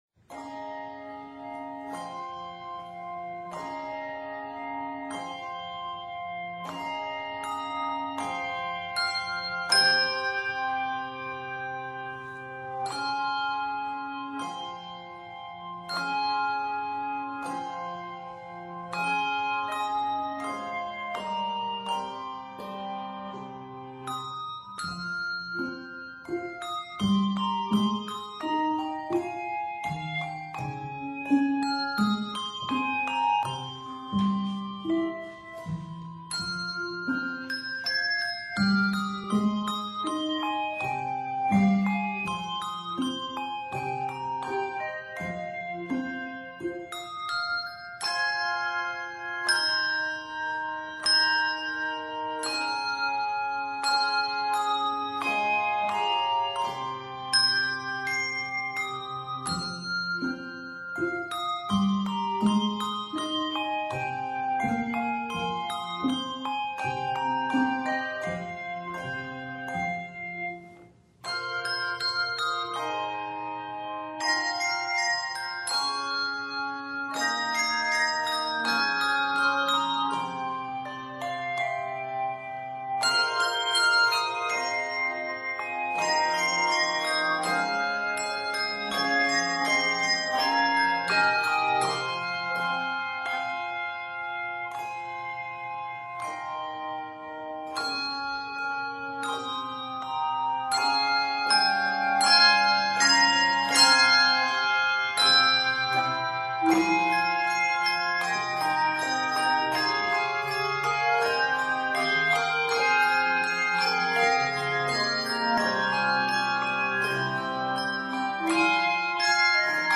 Thus, there is a gentle Native-American aura about the work.